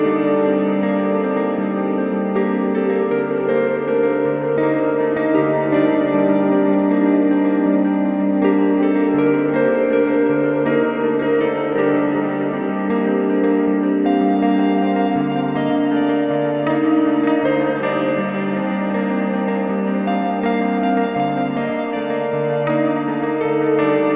012-ad-infinitum-loop.wav